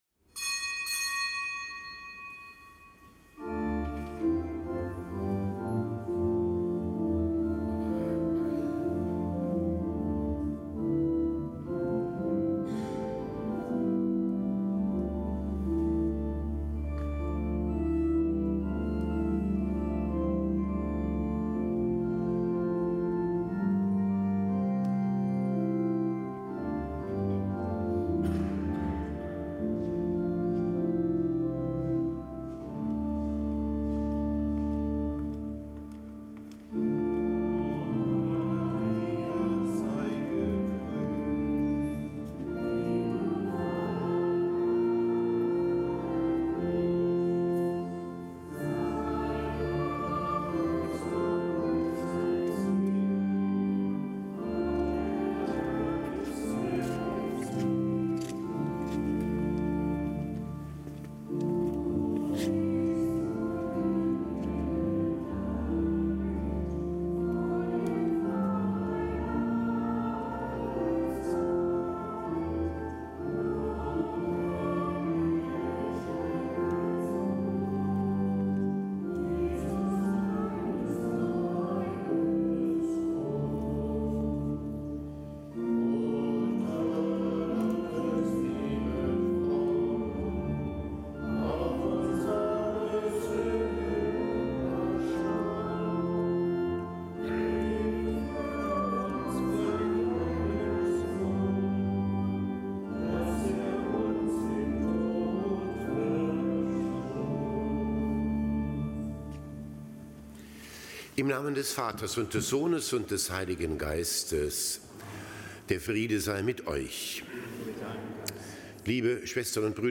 Kapitelsmesse am Montag der achtundzwanzigsten Woche im Jahreskreis
Kapitelsmesse am Montag der achtundzwanzigsten Woche im Jahreskreis das Kapitelsamt aus dem Kölner Dom mit Weihbischof Dominikus Schwaderlapp.